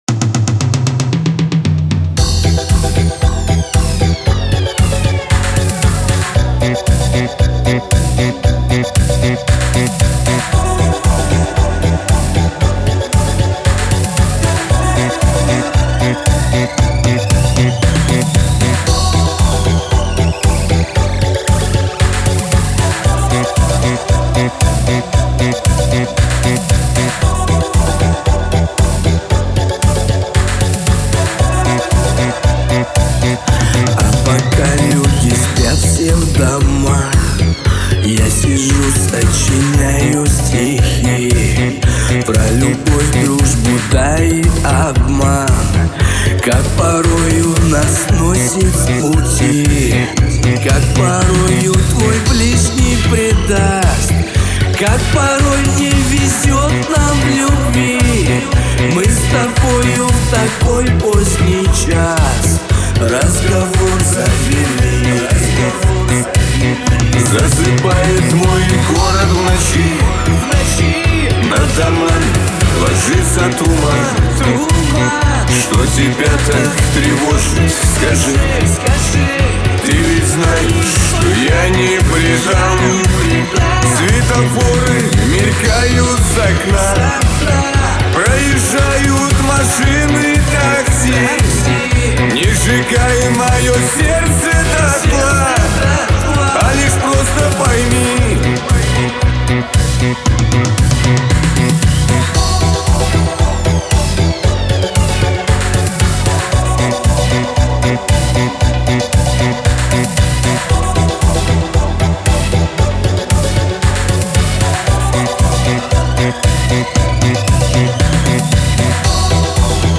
Жанр: Chanson